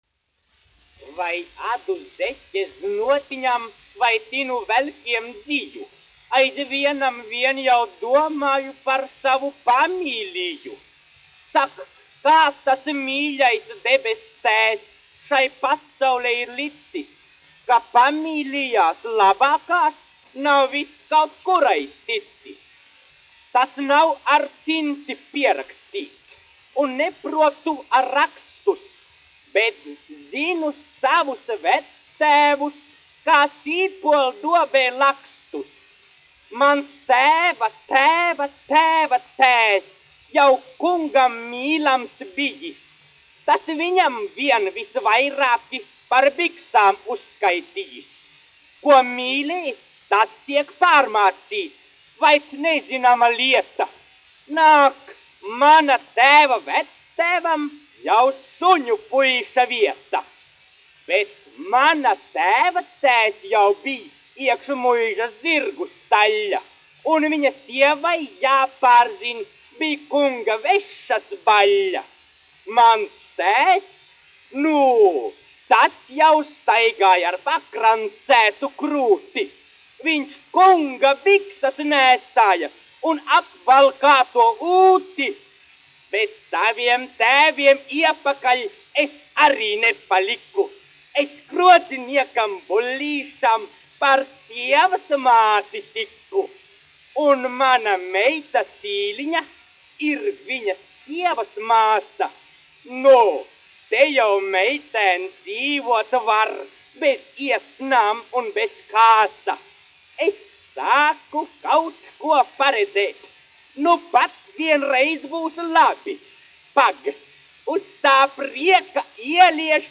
Buļļu krodzinieka sievas mātes radi : deklamācija
1 skpl. : analogs, 78 apgr/min, mono ; 25 cm
Izpildītājs: Rīgas Jaunā Latviešu teātra aktieris
Latvijas vēsturiskie šellaka skaņuplašu ieraksti (Kolekcija)